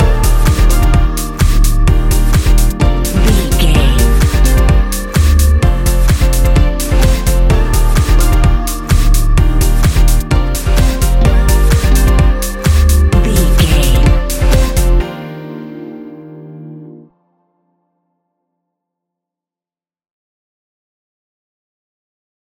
Ionian/Major
D♯
house
electro dance
synths
techno